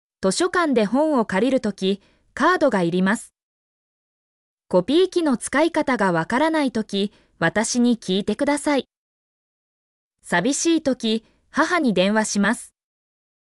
例文：
mp3-output-ttsfreedotcom-42_bNLFlCH2.mp3